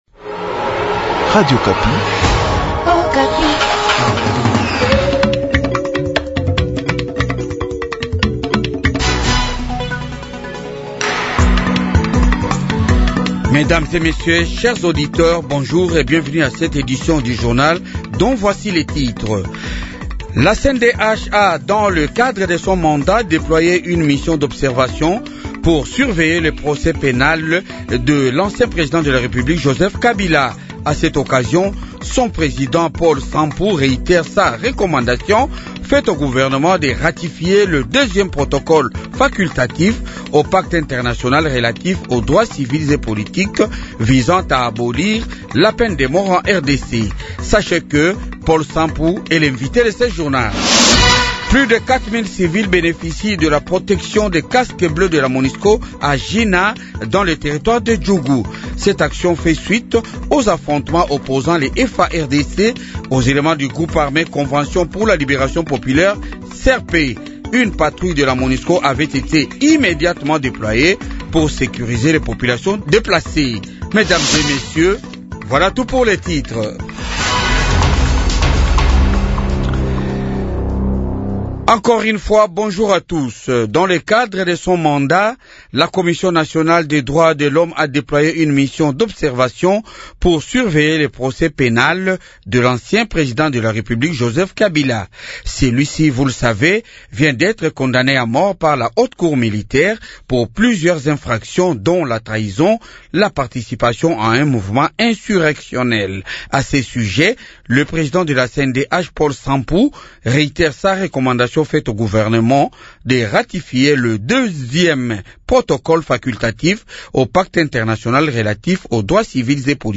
Journal Francais matin 8H